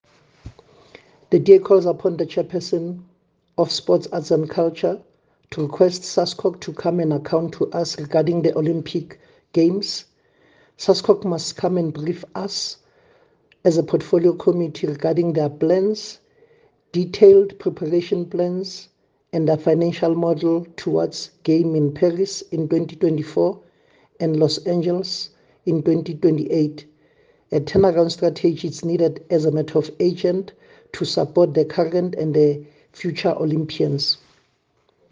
soundbite by Tsepo Mhlongo MP.